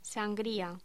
Locución: Sangría
voz
Sonidos: Voz humana